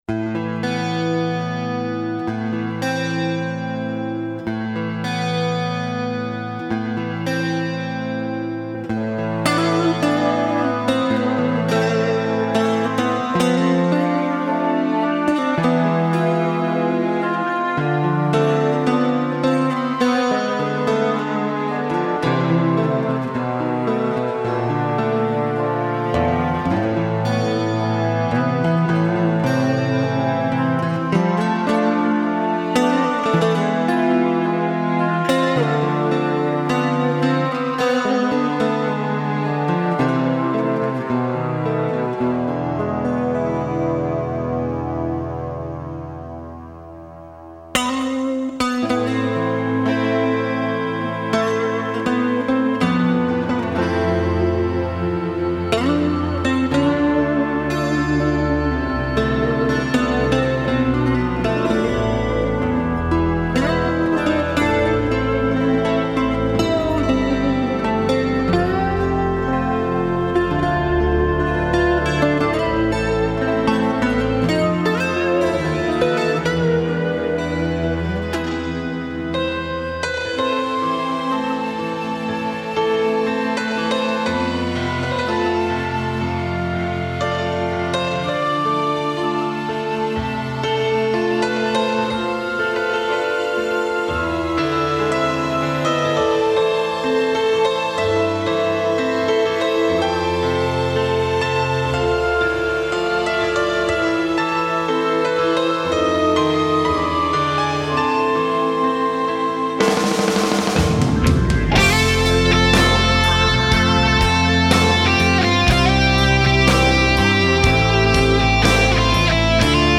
Guitar solo